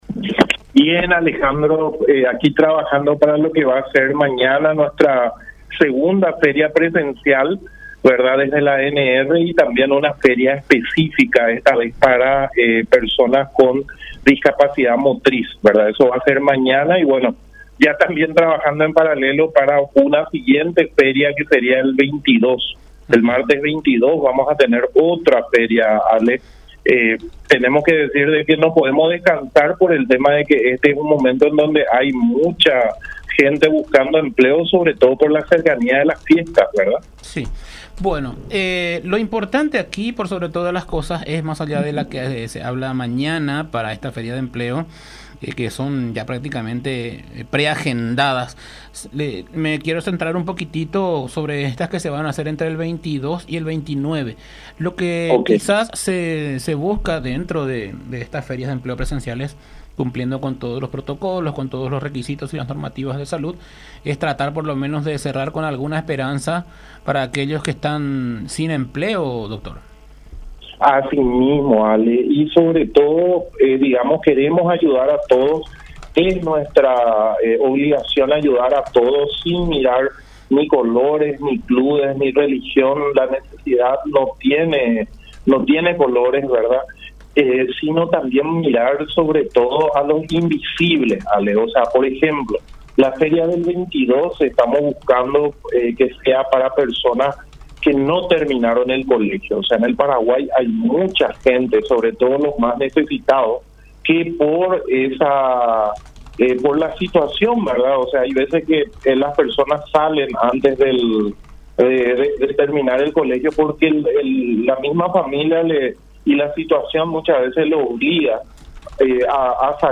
en contacto con La Unión R800 AM